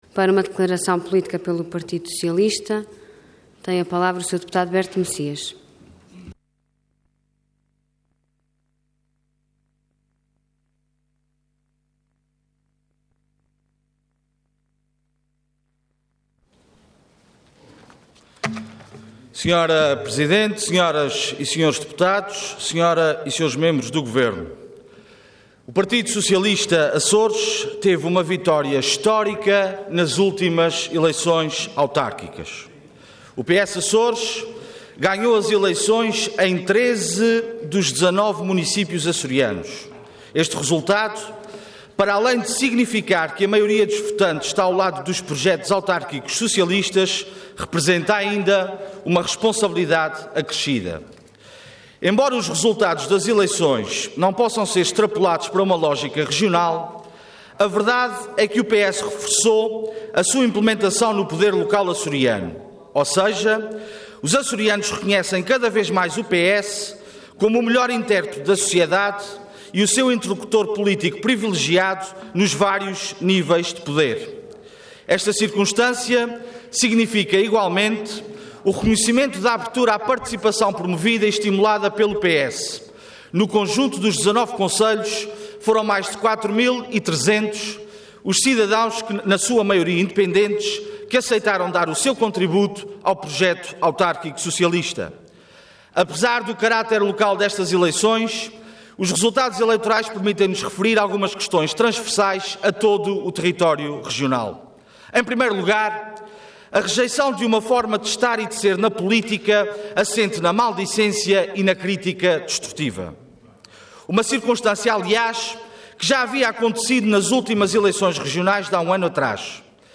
Website da Assembleia Legislativa da Região Autónoma dos Açores
Intervenção Declaração Política Orador Berto Messias Cargo Deputado Entidade PS